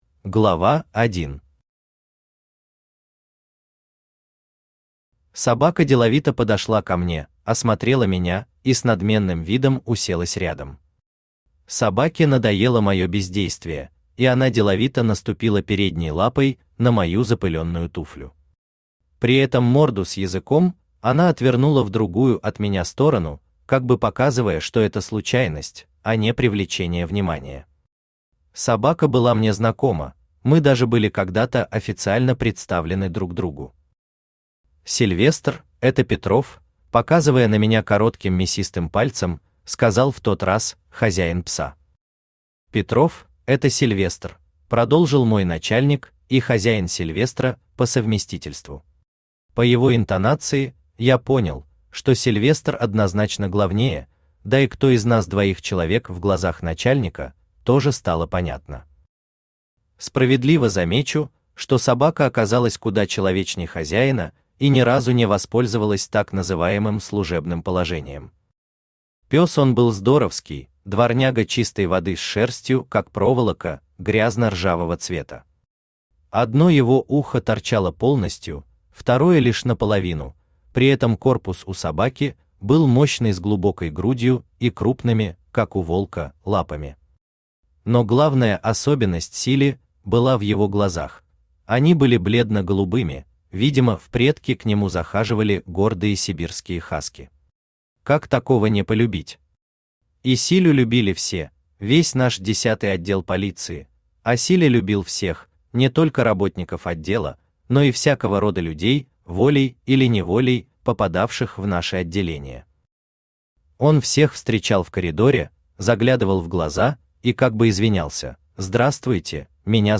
Аудиокнига Адам | Библиотека аудиокниг
Aудиокнига Адам Автор Леонид Сергеевич Акимкин Читает аудиокнигу Авточтец ЛитРес.